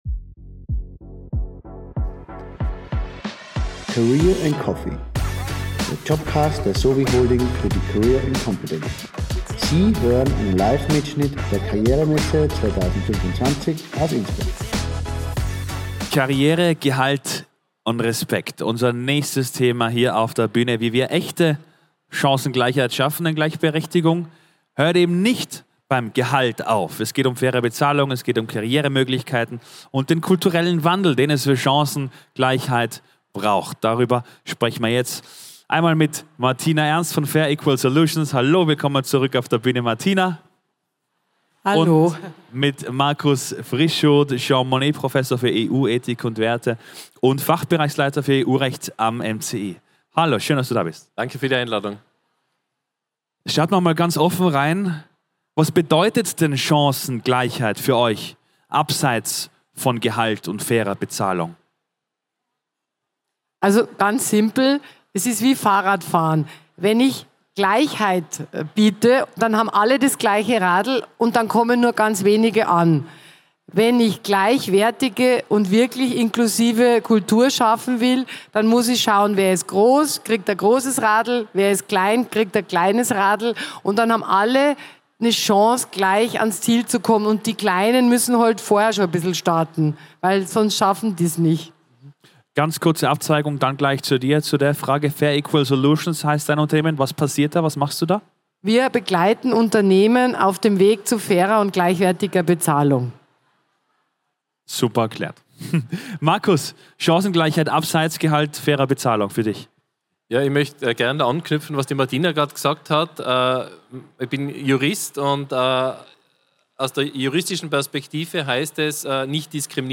Livemitschnitt #7 von der career & competence am 14. Mai 2025 im Congress Innsbruck.